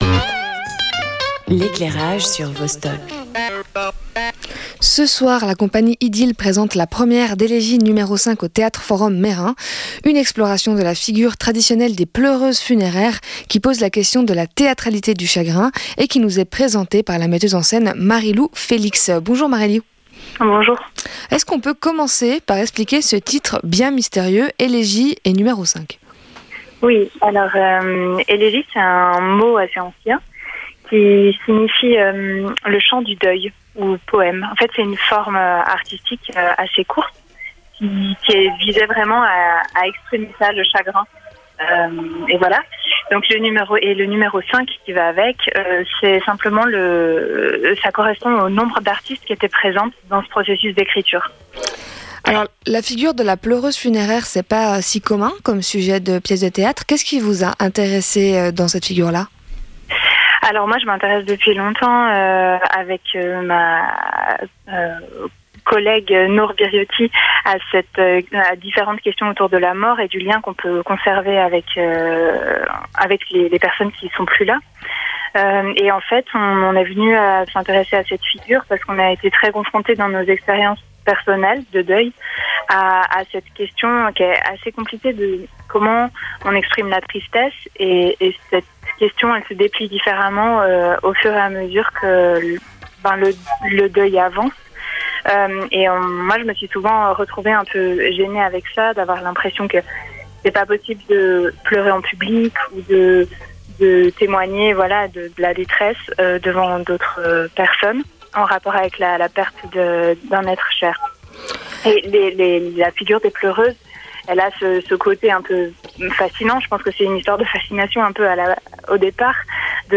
Première diffusion antenne : 1er avril 2025